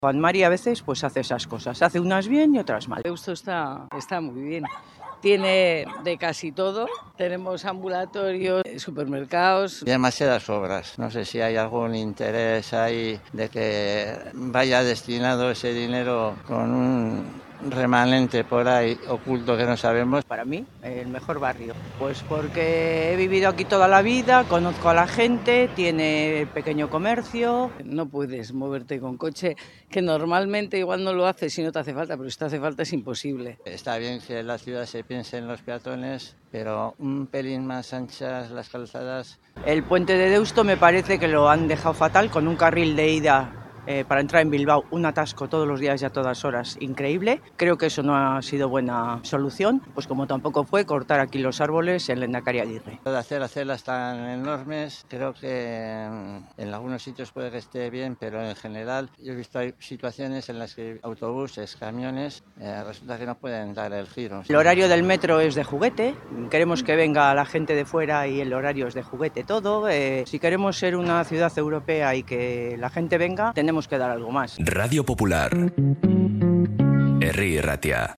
En 'Bilbao al habla' conocemos la opinión de los vecinos de Deusto
En Bilbao al Habla, los vecinos han compartido sus opiniones sobre los aspectos positivos y los retos que enfrenta su barrio.